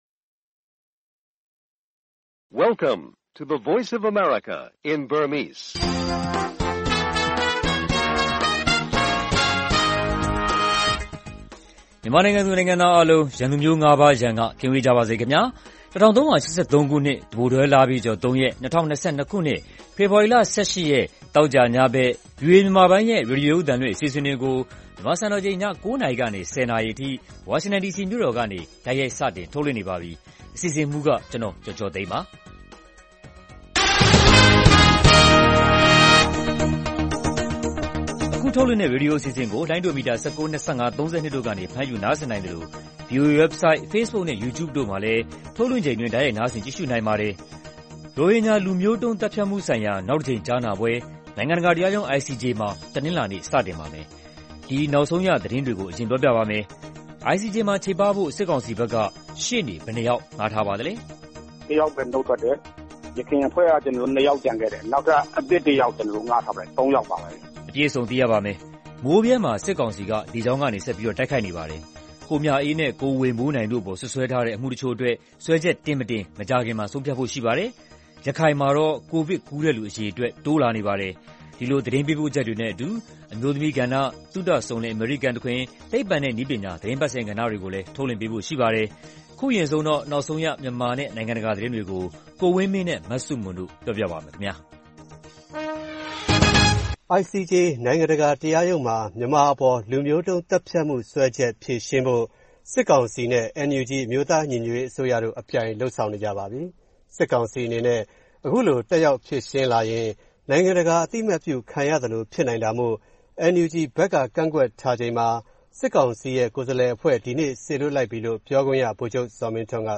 ဗွီအိုအေ သောကြာ ၉း၀၀-၁၀း၀၀ နာရီ ရေဒီယို/ရုပ်သံလွှင့်အစီအစဉ်